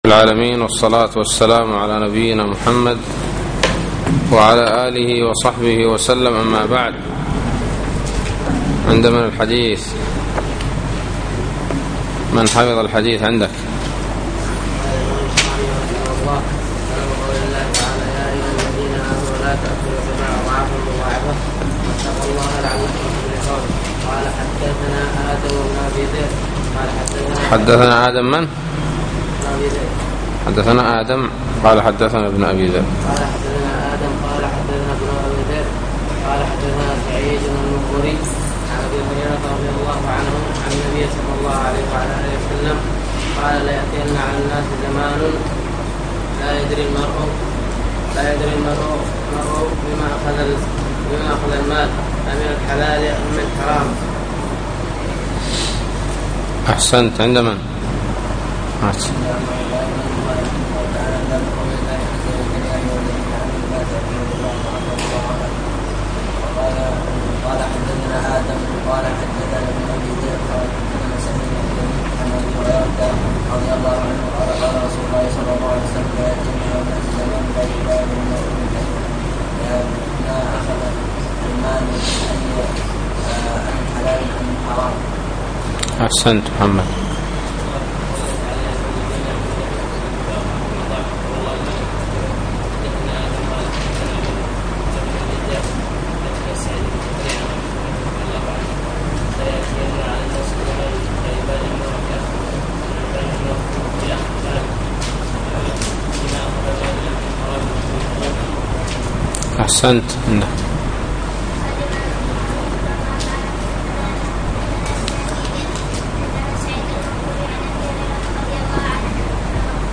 الدرس الواحد والعشرون : بَاب: آكِلِ الرِّبَا وَشَاهِدِهِ وَكَاتِبِهِ